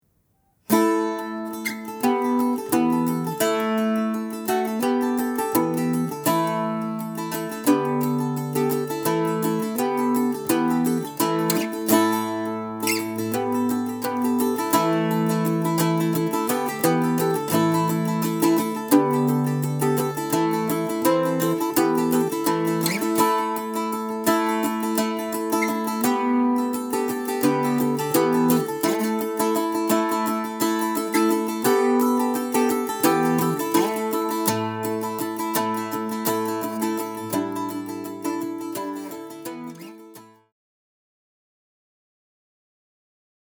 Bouzouki Accompaniment - Easy Online Lessons - Online Academy of Irish Music
Bouzouki-1.mp3